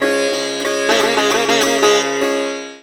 SITAR GRV 13.wav